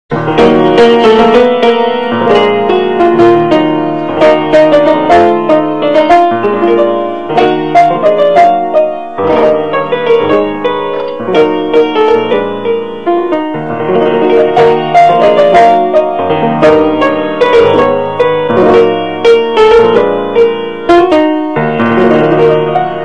инструментальные